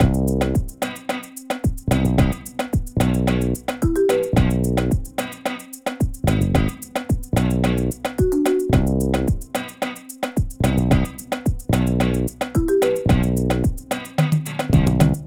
アフリカのアフロビートのリズムを取り入れたクールめなBGMです！
ループ：◎
BPM：110 キー：C
楽器：ベース、ギター、ストリングス